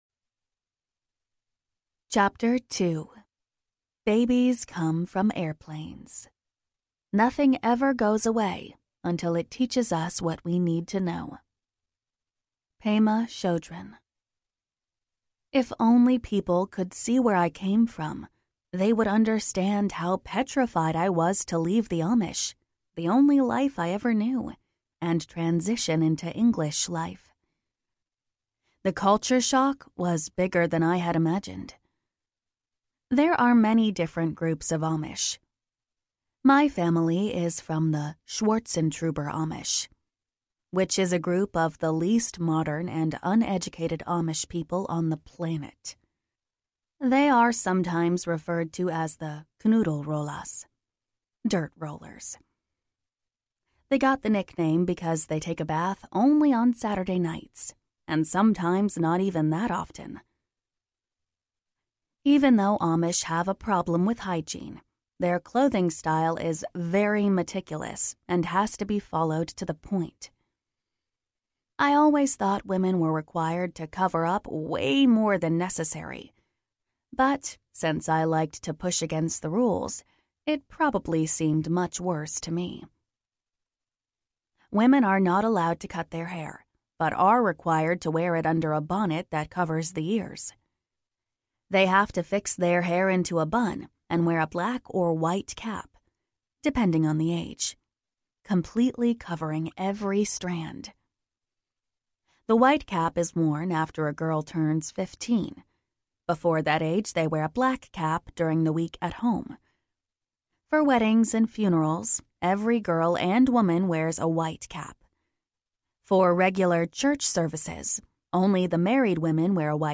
Runaway Amish Girl Audiobook
Narrator